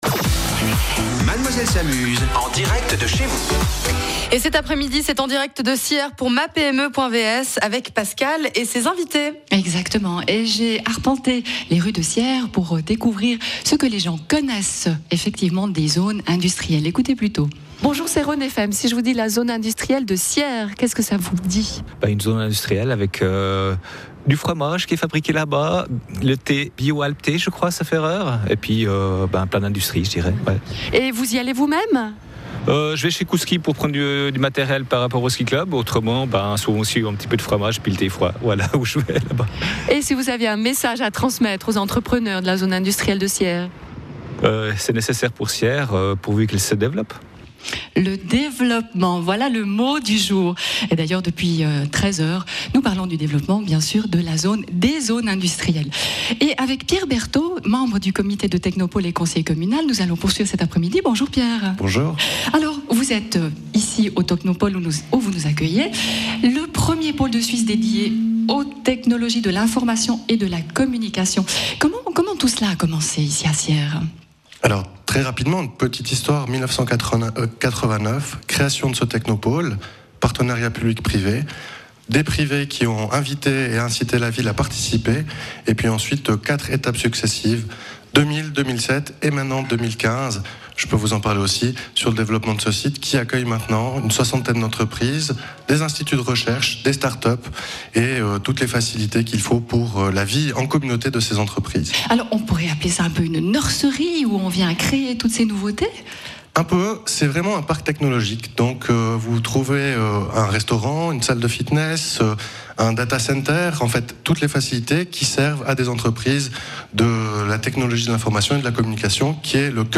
Suite à une présentation du tissu économique par le Président de la ville, les interviews en direct de plusieurs entrepreneurs vous permettront de mieux connaitre une commune et son économie !
Interview de M.